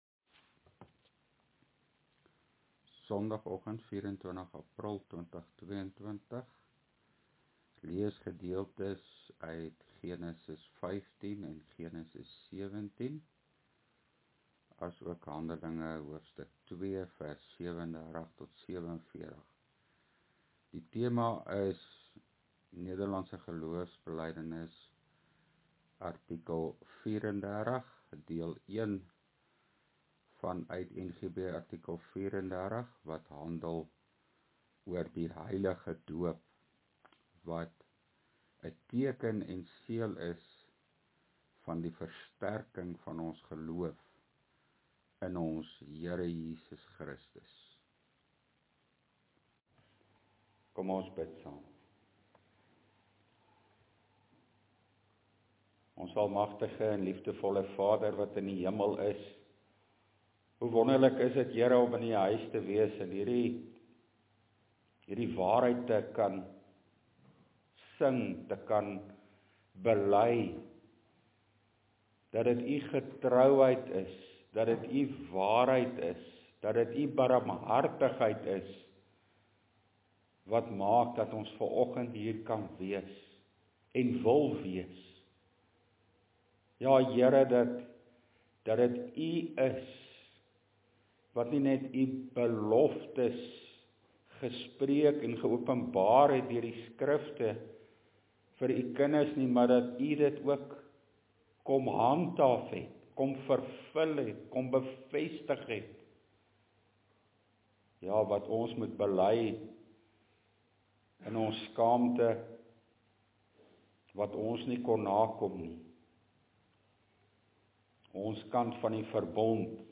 LEER PREDIKING: NGB artikel 34 – Die heilige doop (Hand. 2:37-47, deel 1)